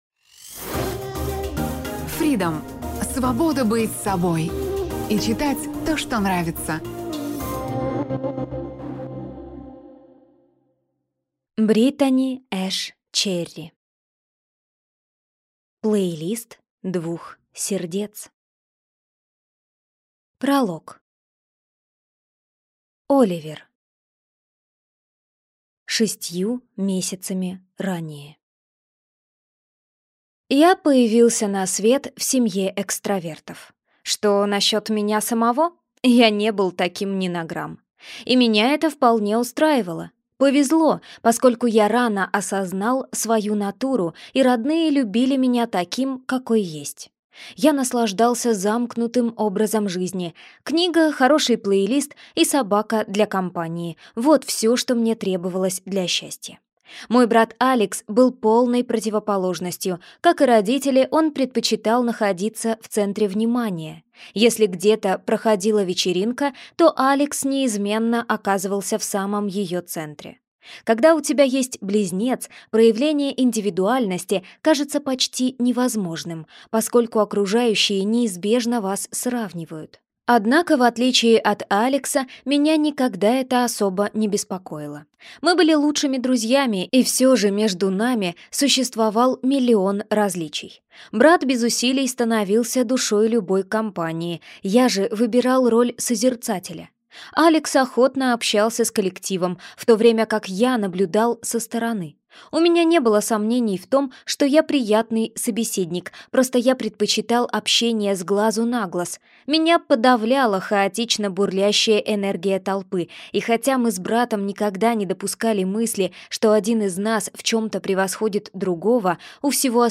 Аудиокнига Плейлист двух сердец | Библиотека аудиокниг